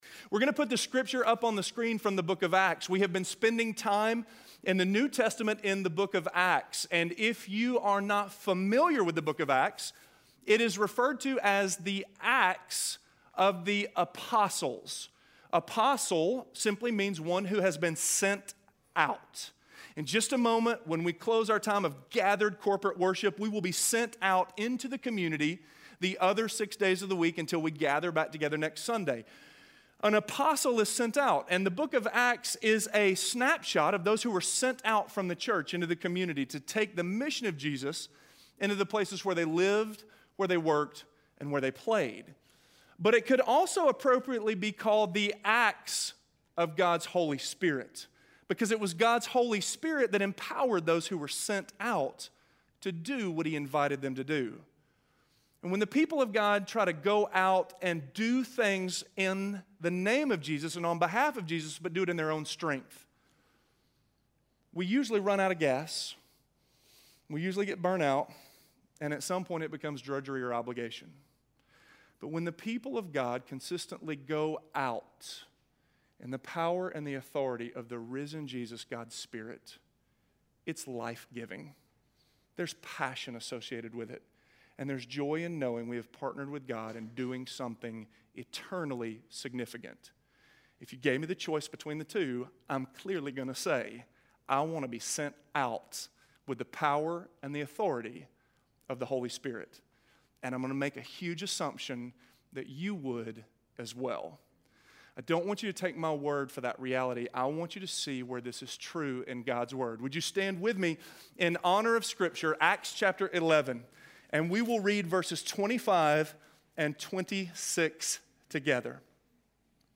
Just Like Barnabas: Leading - Sermon - Avenue South